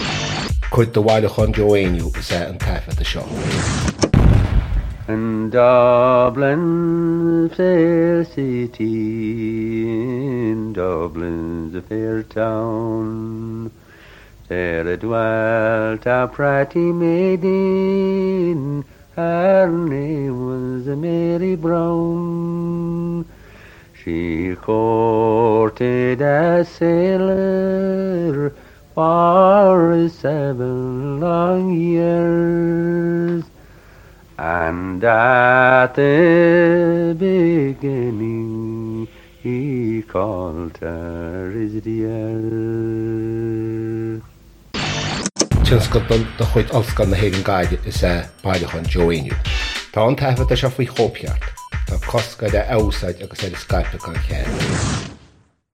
• Catagóir (Category): song.
• Ainm an té a thug (Name of Informant): Joe Heaney.
• Suíomh an taifeadta (Recording Location): London, England.
• Ocáid an taifeadta (Recording Occasion): private gathering at the home of Ewan Mac Coll and Peggy Seeger.
This fragment was recorded by Ewan Mac Coll and Peggy Seeger in December, 1963, at the same time that they recorded the items subsequently released in the two-disc album, The Road from Conamara.
A comparison reveals that when singing in Irish Joe uses a great deal more of the ornamentation associated with the ‘sean-nós’ style than he does in the English-language song.